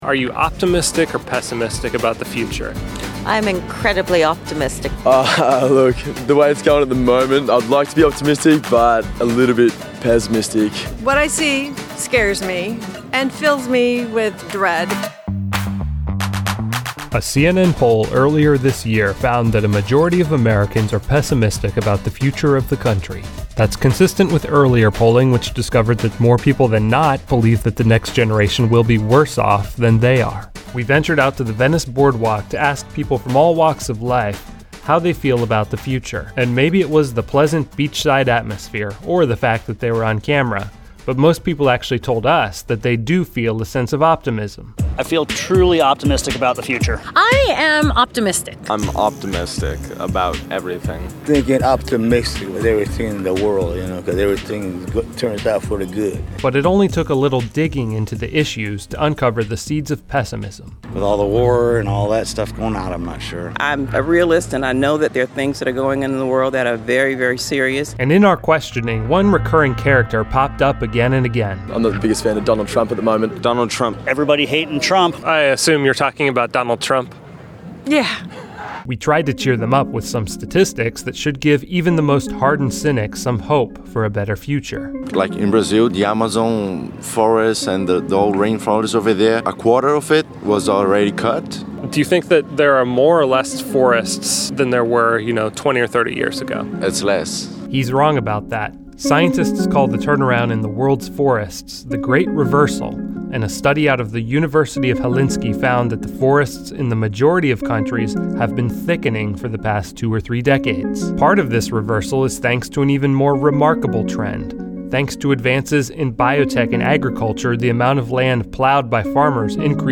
Reason TV went to the Venice Beach boardwalk to gage how people are feelings about the future, with the goal of cheering up the doomsayers.